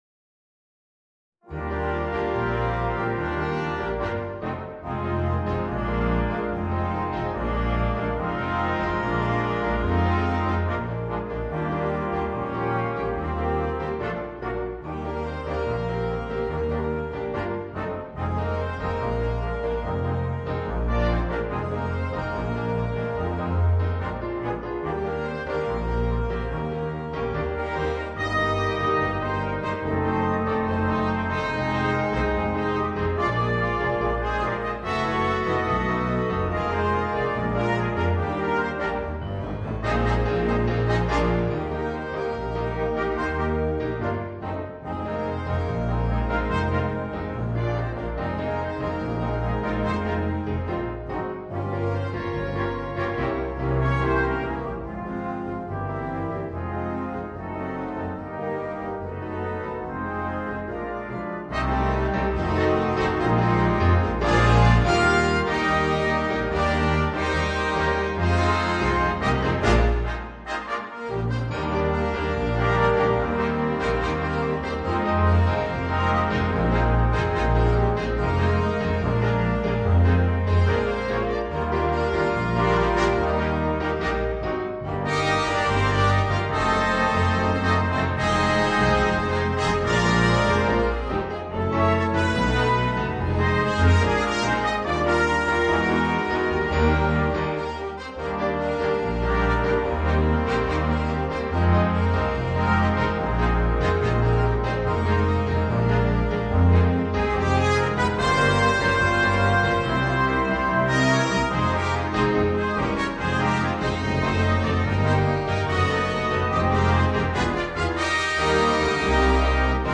Orchestre à Vent